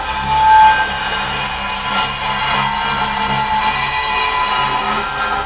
metalLightOnMetal_lp.WAV